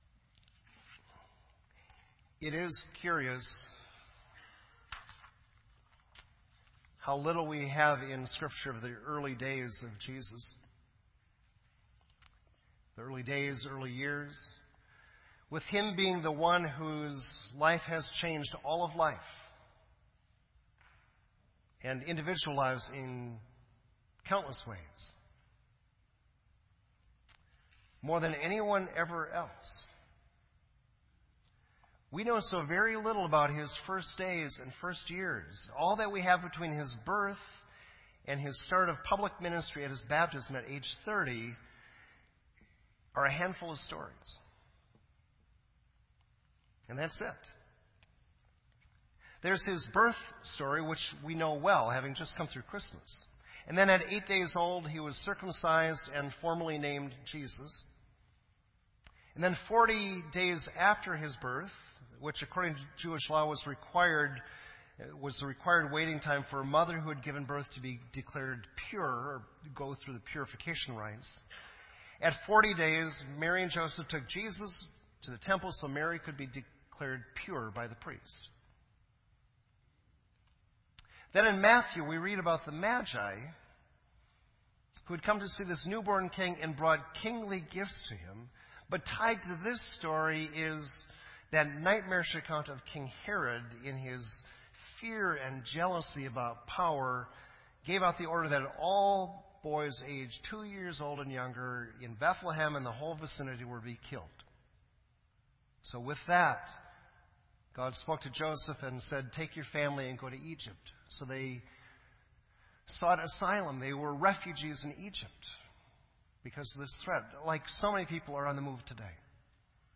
This entry was posted in Sermon Audio on January 2